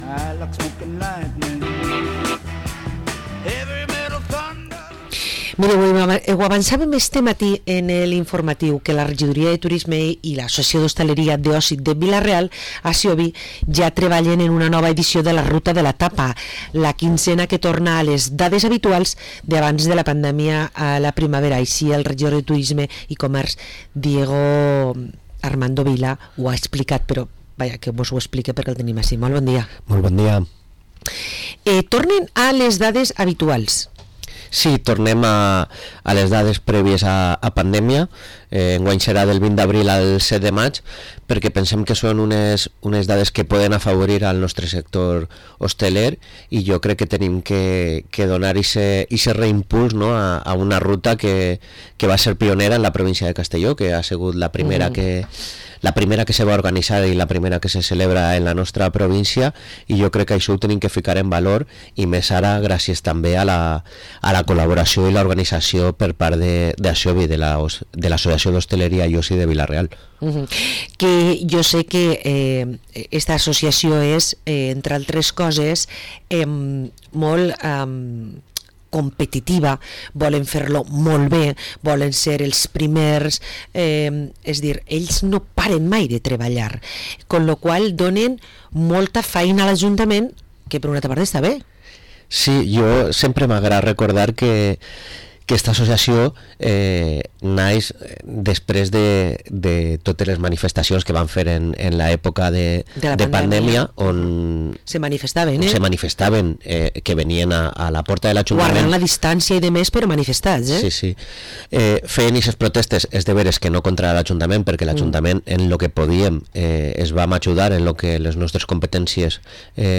Entrevista a Diego Vila, regidor de turisme i comerç, ens parla d´una nova edició de la «Ruta de la Tapa»